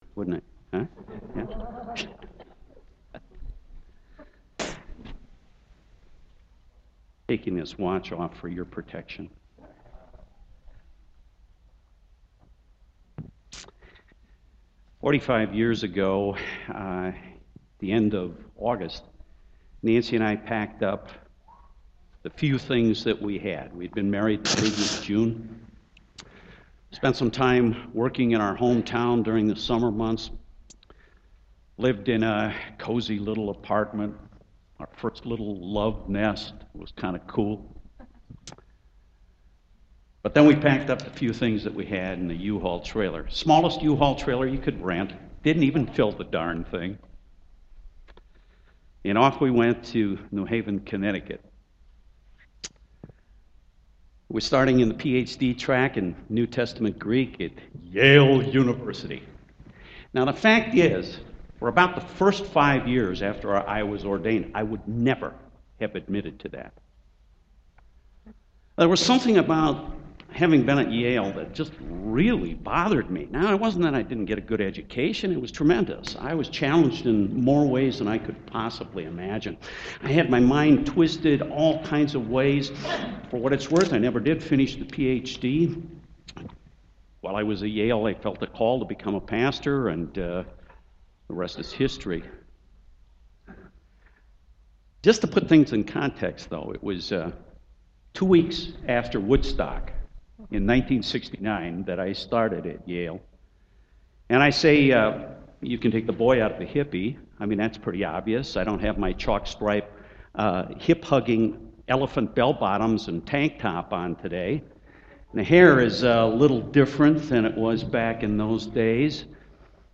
Sermon 11.9.2014